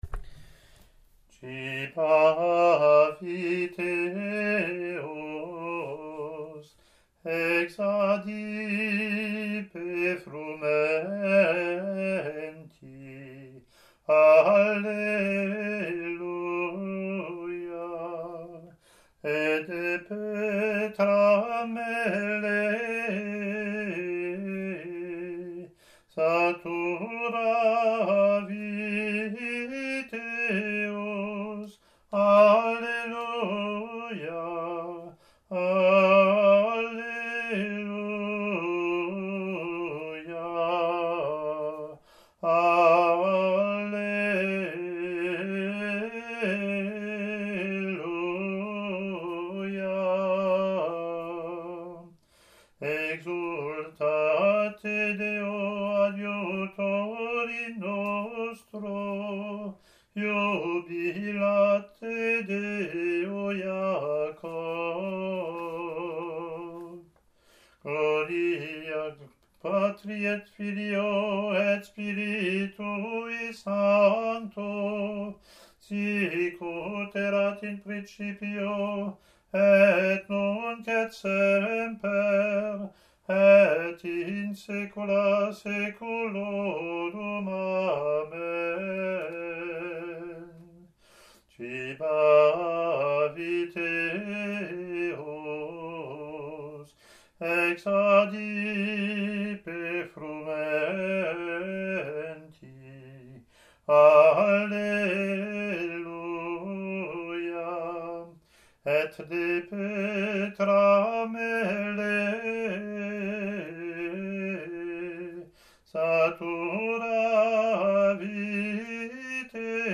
Latin antiphon and verse)